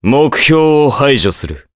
Crow-Vox_Skill1_jp.wav